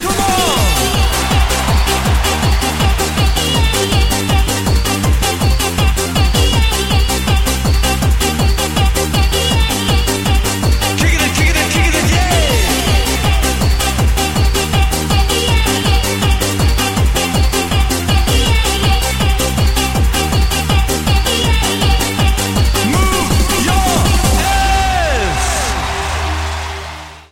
• Качество: 128, Stereo
танцевальная